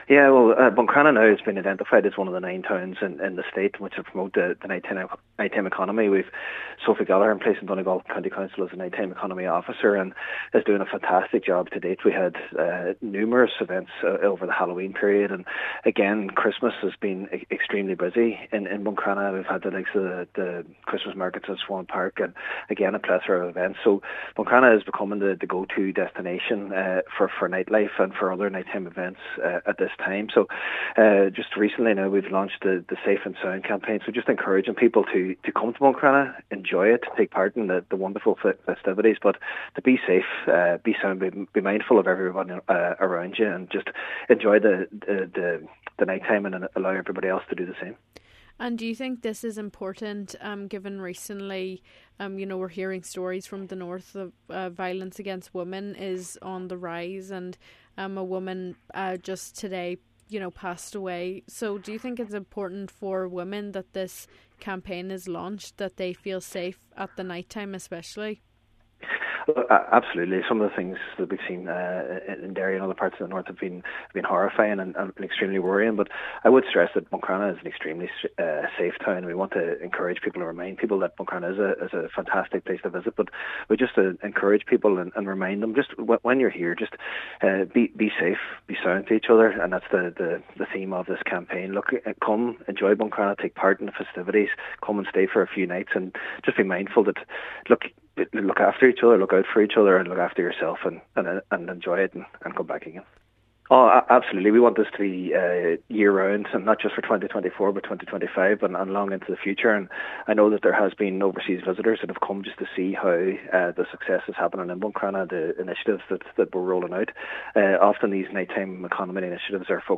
Cathaoirleach of the Inishowen Municipal District Jack Murray says the campaign will ensure Buncrana is a welcoming place for everyone.